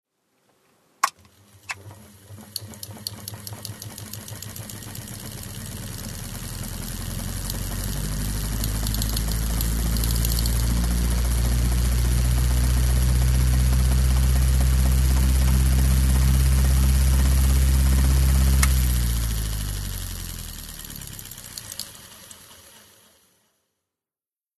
Switching on and off
00157_Ein-_und_ausschalten.mp3